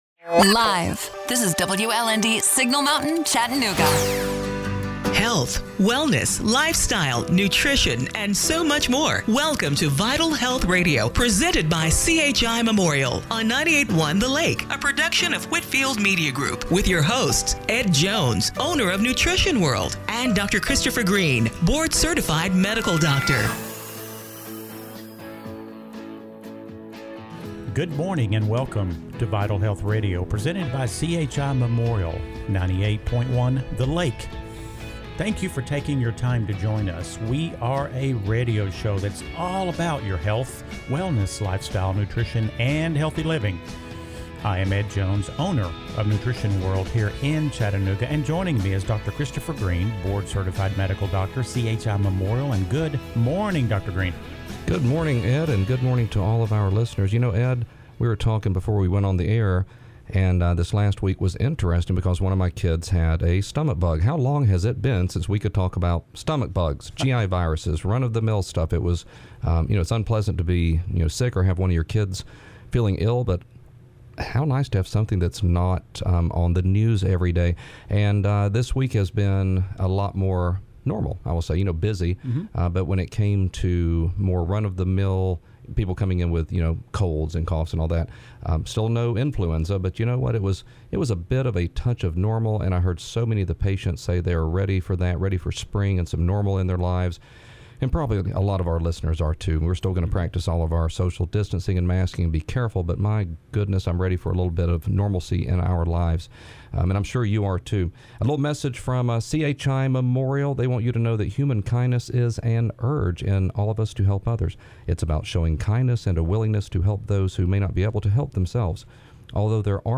February 28, 2021 – Radio Show - Vital Health Radio